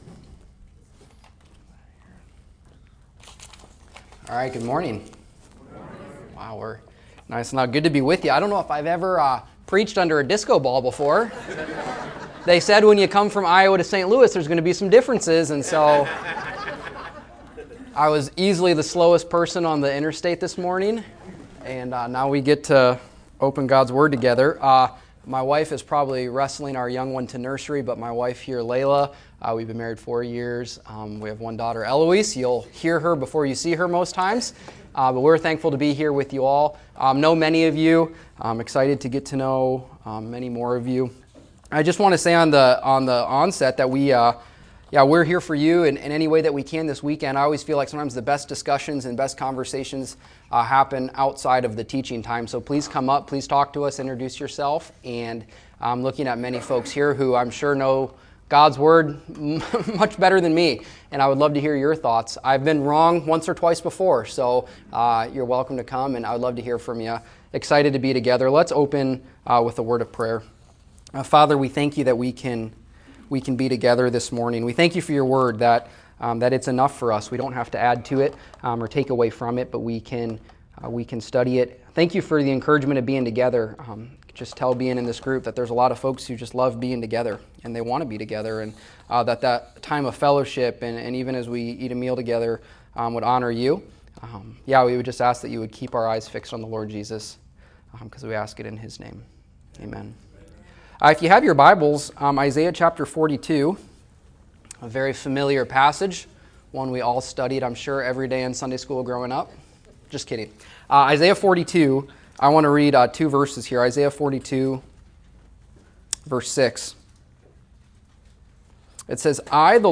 Held March 7, 2026, at Royal Orleans Banquet Center.